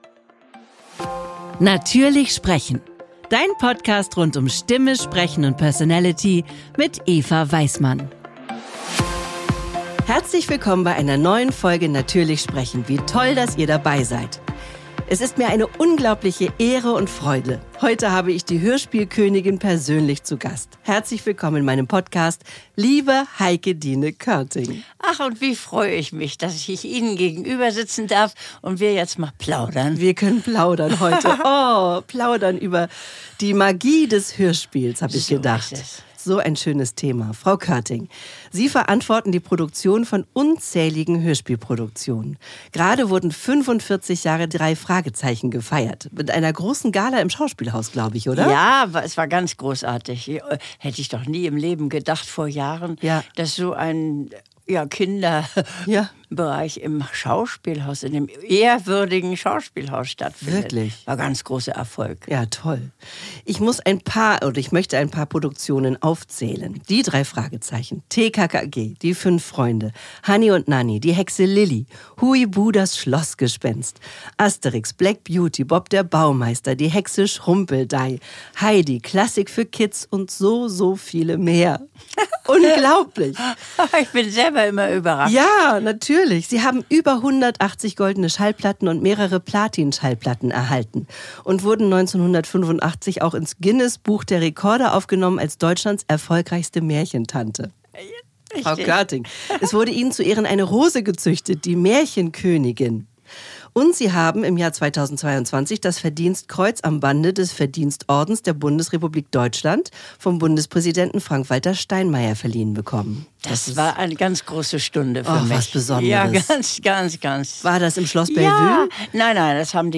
Podcast #25 Heikedine Körting: Die Magie des Hörspiels Erweiterte Suche #25 Heikedine Körting: Die Magie des Hörspiels vor 1 Jahr Im Gespräch mit der "Hörspielkönigin" Heikedine Körting. 1 Stunde 21 Minuten 65.8 MB Podcast Podcaster Natürlich sprechen!